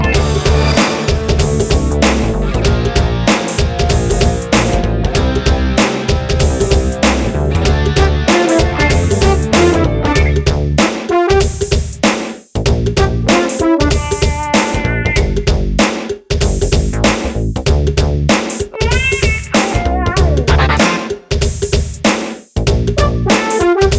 Pop (1980s) 2:44 Buy £1.50